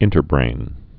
(ĭntər-brān)